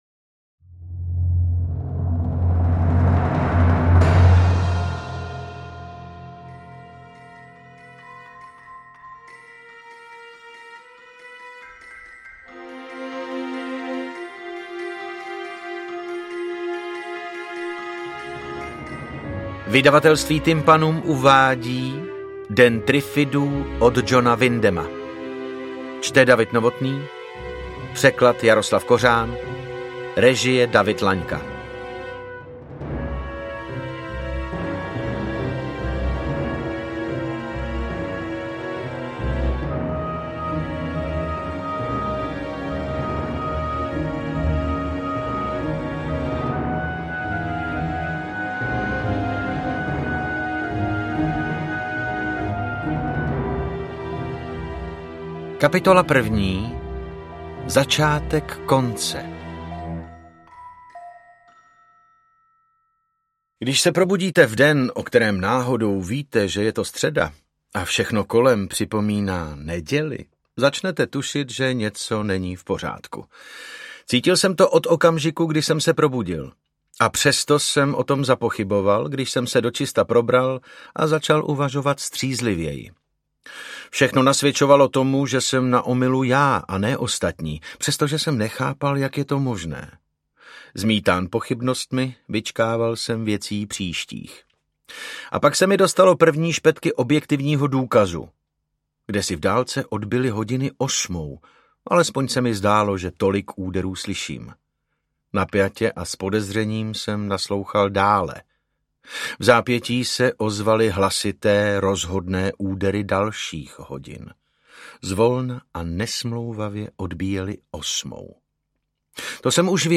Interpret:  David Novotný
AudioKniha ke stažení, 27 x mp3, délka 10 hod. 51 min., velikost 595,9 MB, česky